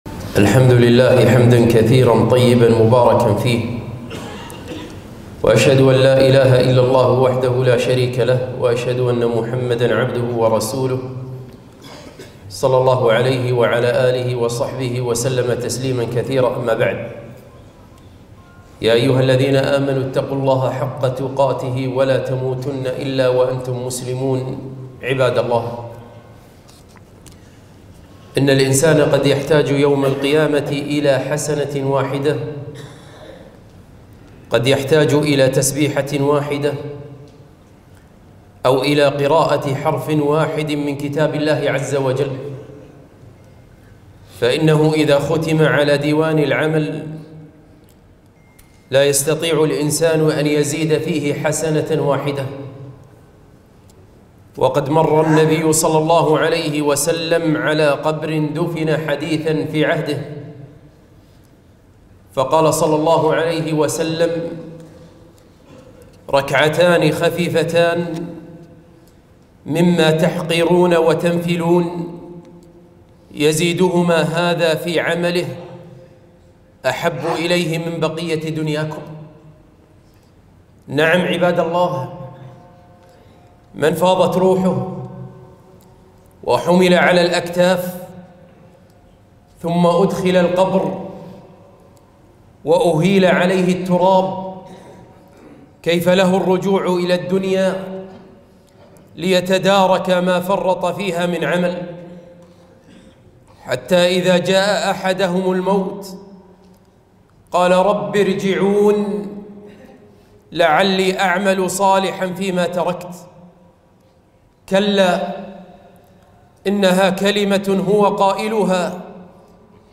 خطبة - رمضان مزرعة العباد